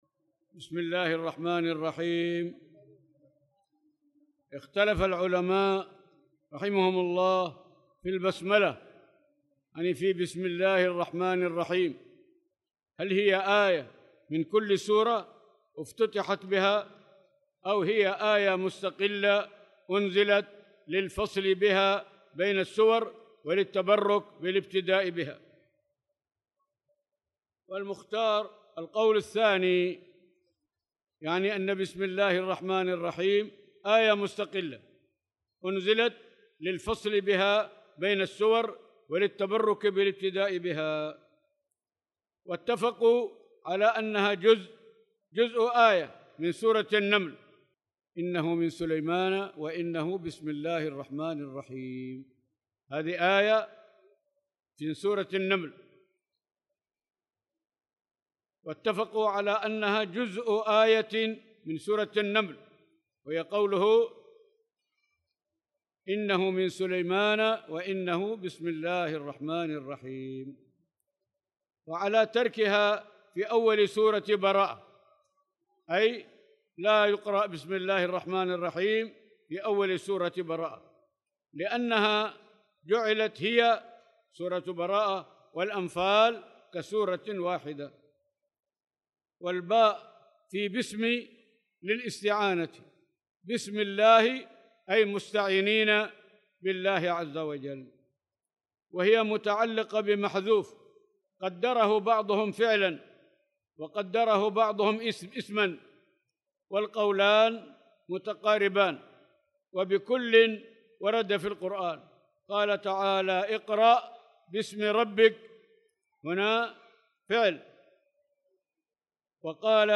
تاريخ النشر ١٥ شعبان ١٤٣٧ هـ المكان: المسجد الحرام الشيخ